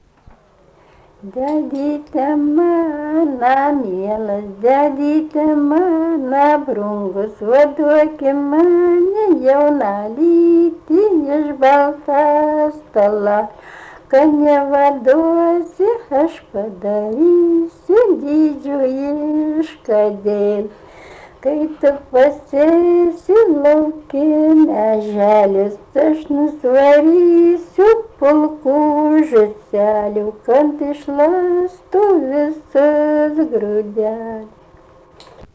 rauda